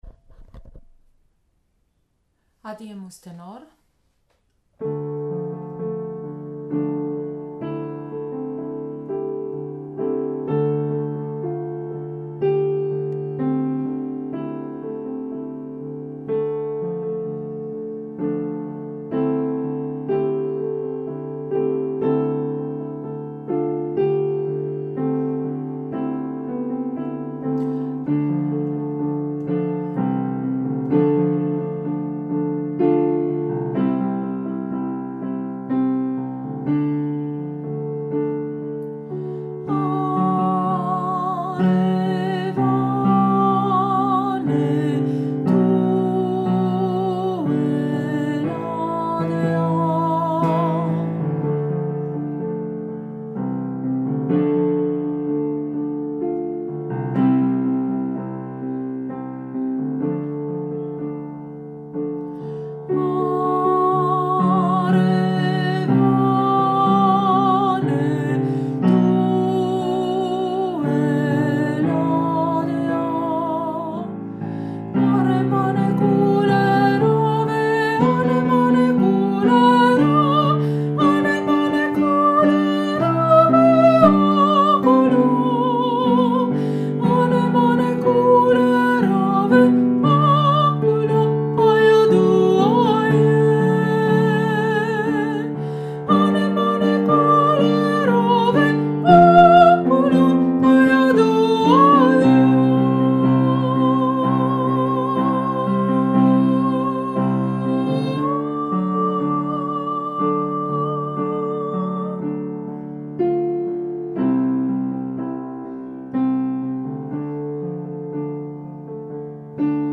04 - Tenor - ChorArt zwanzigelf - Page 19